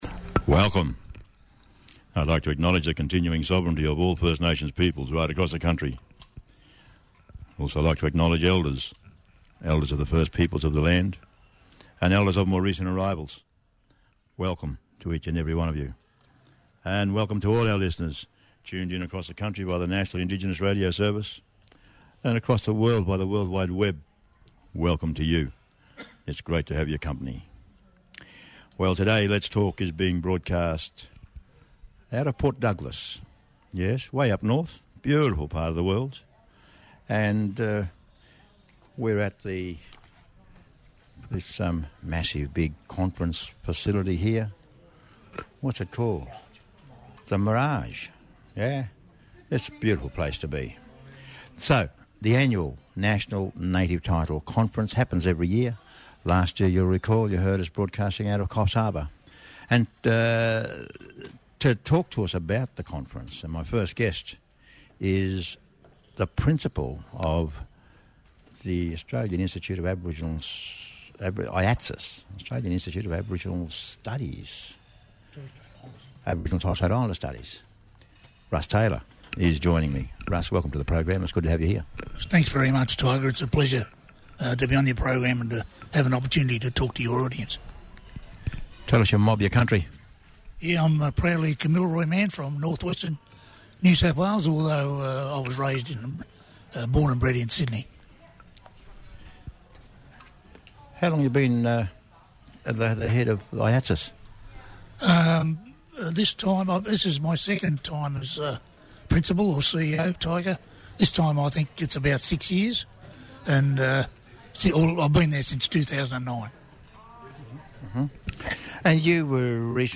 Special 2 hour live broadcast from the National Native Title Conference held on the traditional lands of the Kuku Yalanji people in Port Douglas Queensland.